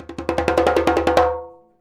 100DJEMB04.wav